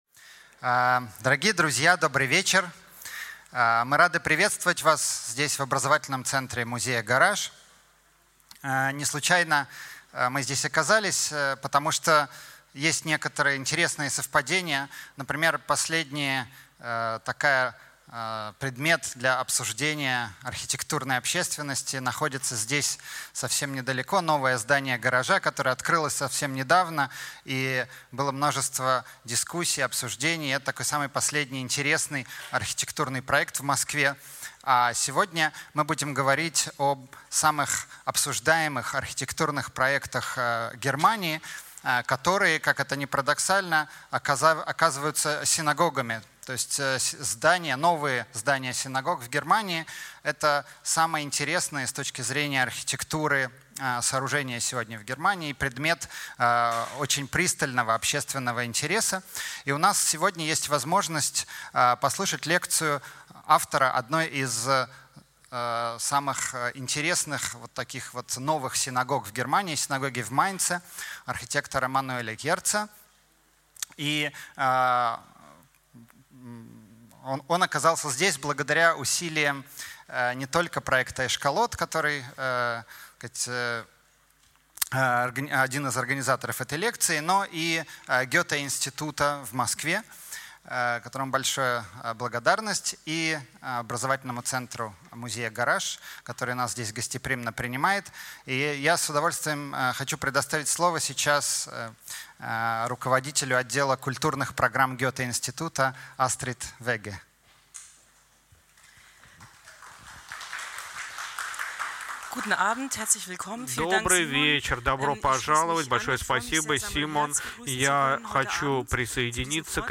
Аудиокнига Традиция и авангард в архитектуре немецких синагог | Библиотека аудиокниг